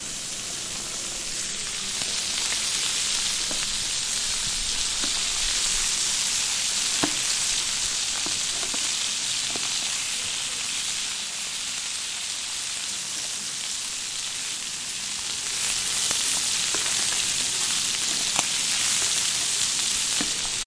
煎锅煎培根
描述：用铸铁煎锅煎培根
Tag: 家务 油炸 嘶嘶声 培根 pan 食物 烹饪